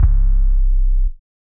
muffled 808.wav